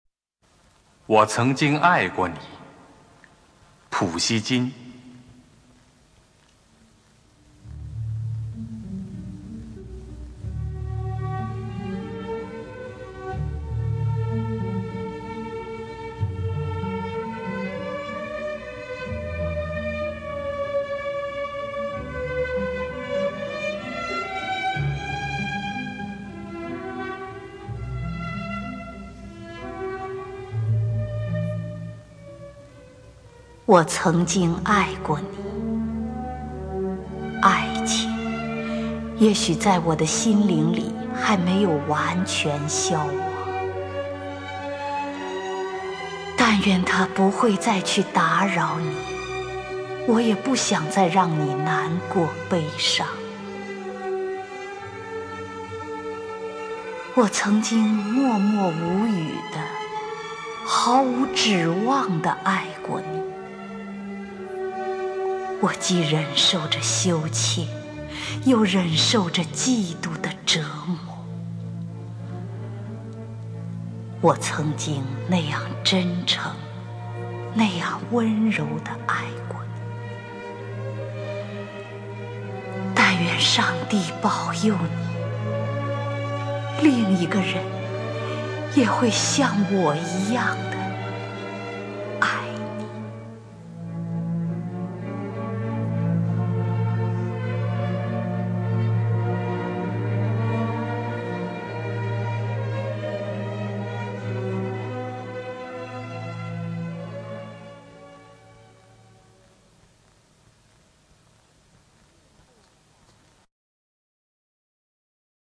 首页 视听 经典朗诵欣赏 丁建华、乔榛：外国爱情诗配乐朗诵